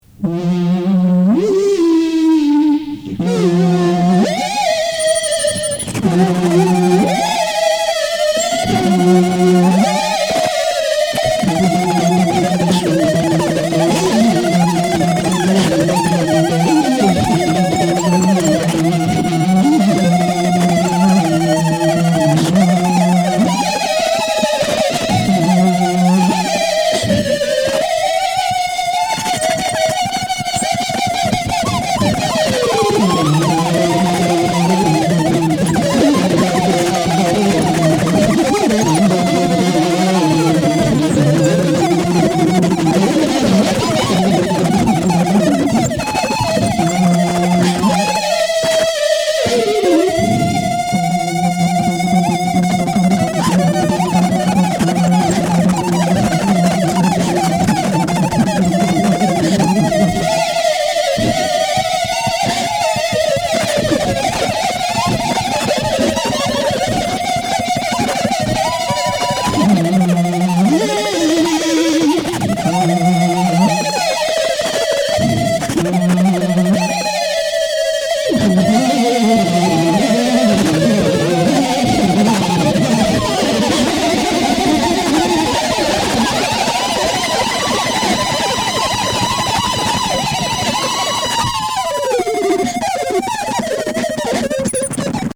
Note that I cannot be remotely responsible for any irrevocable damage incurred by listening to this audio catastrophe.